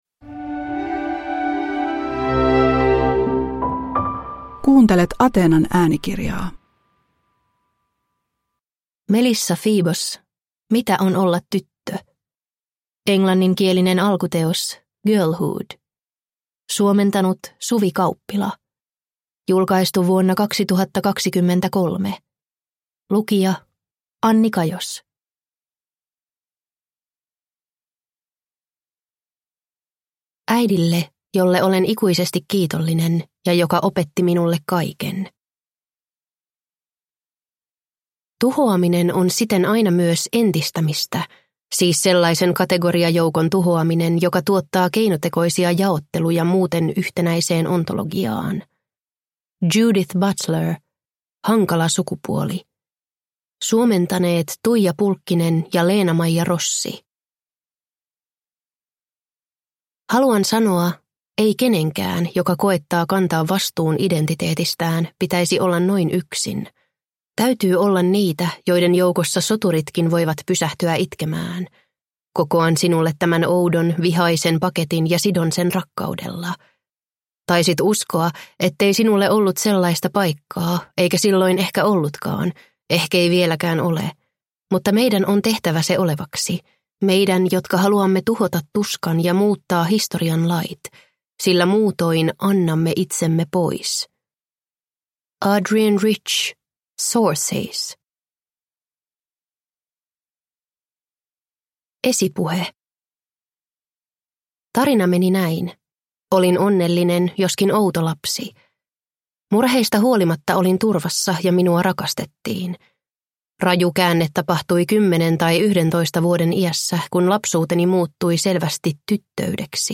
Mitä on olla tyttö – Ljudbok